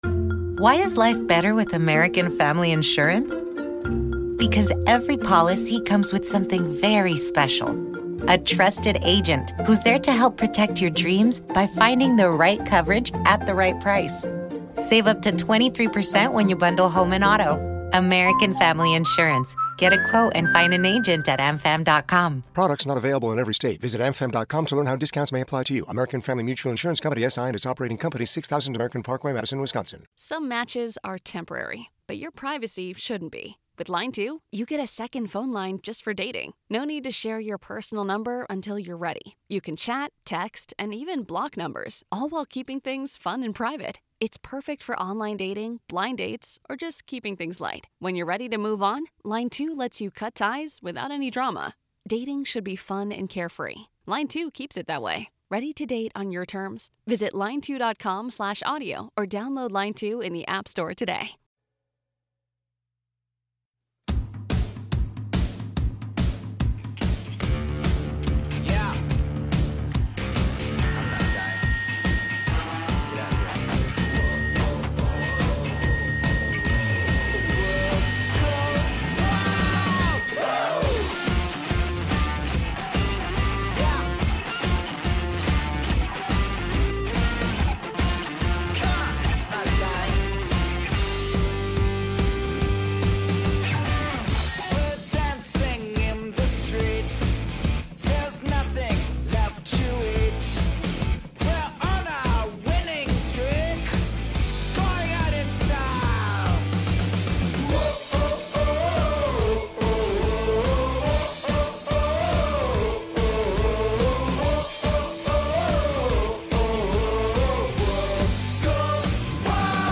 We believe debates should be moderated on an equal playing field and host live debates on controversial topics from science, religion, and politics several times a week.